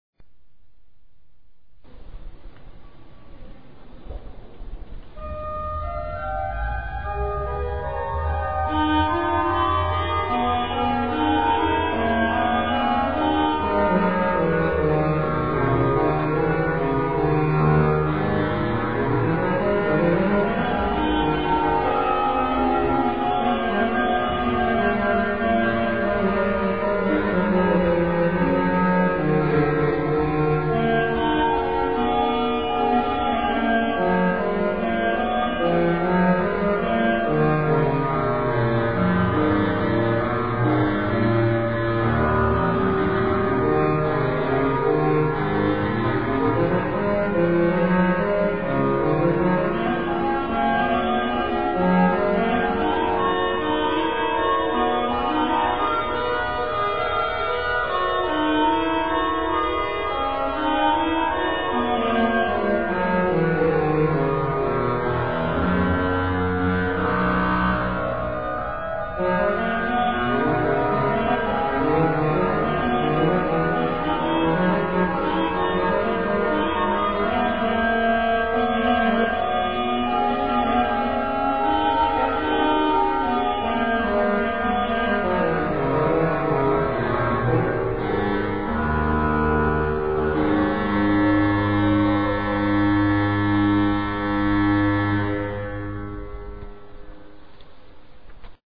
FILES AUDIO DAL VIVO
(organo della chiesa di Saint Martin a Dieppe - Normandia - Francia)
organo